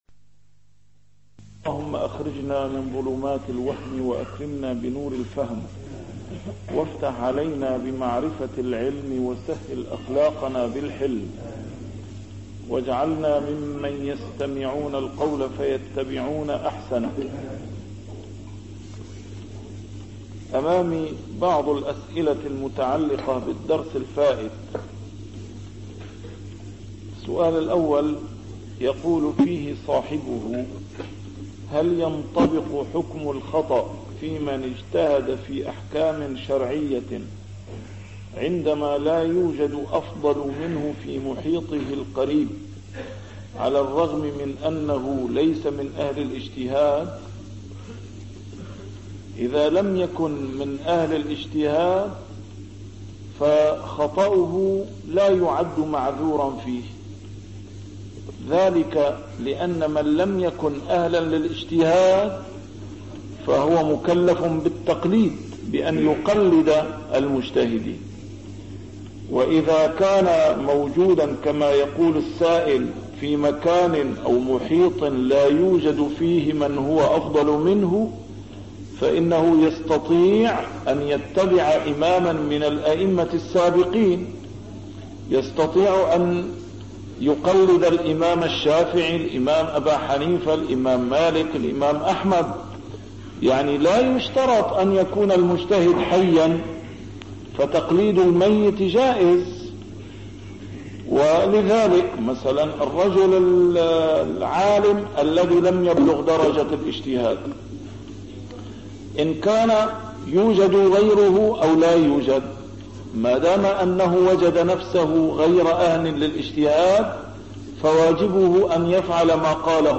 A MARTYR SCHOLAR: IMAM MUHAMMAD SAEED RAMADAN AL-BOUTI - الدروس العلمية - شرح الأحاديث الأربعين النووية - تتمة شرح الحديث التاسع والثلاثون: حديث ابن عباس (إن الله تجاوزَ لي عن أمتي الخطأَ) 134